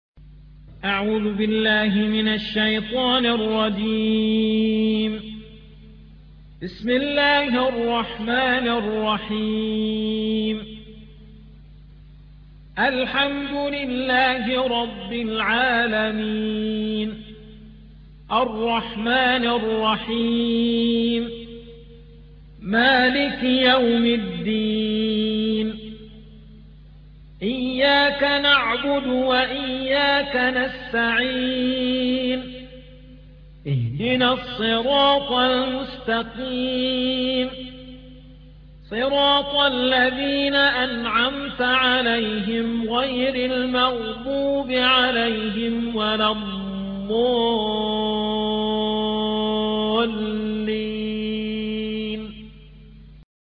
سورة الفاتحة مكية عدد الآيات:7 مكتوبة بخط عثماني كبير واضح من المصحف الشريف مع التفسير والتلاوة بصوت مشاهير القراء من موقع القرآن الكريم إسلام أون لاين